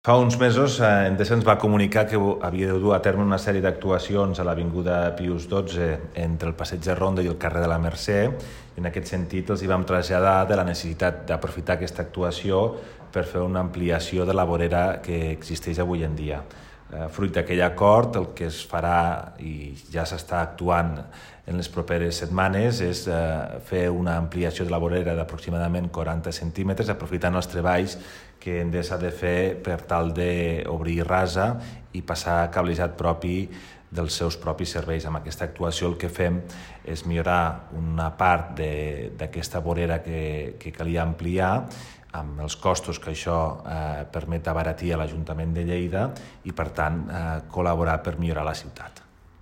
Tall de veu de Toni Postius Compartir Facebook Twitter Whatsapp Descarregar ODT Imprimir Tornar a notícies Fitxers relacionats Tall de veu de Toni Postius sobre aquesta actuació (1.1 MB) T'ha estat útil aquesta pàgina?
tall-de-veu-de-toni-postius-sobre-aquesta-actuacio